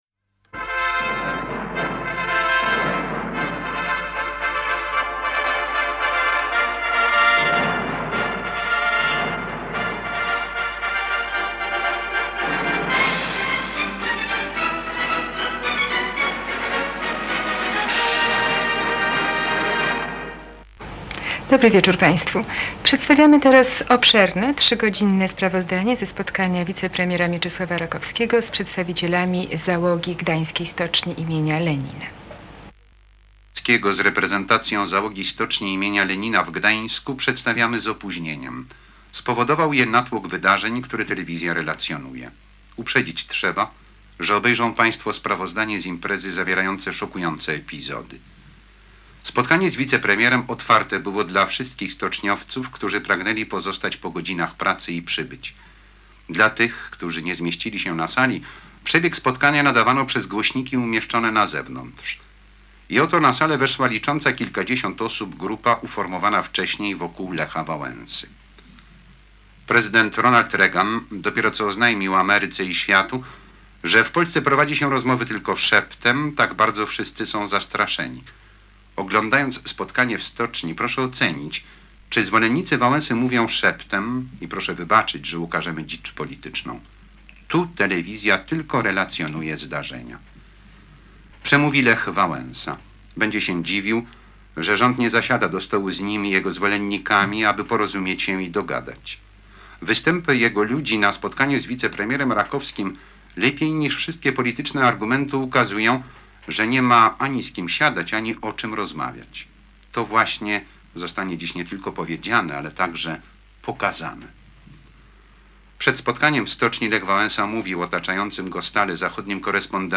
Premier Rakowski w Stoczni Gdańskiej: transmisja TVP [dokument dźwiękowy] - Pomorska Biblioteka Cyfrowa
Zapowiedzi i komentarz TVP spotkania wicepremiera Mieczysława Rakowskiego z załogą Stoczni Gdańskiej im. Lenina